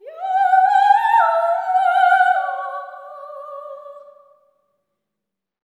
OPERATIC05-L.wav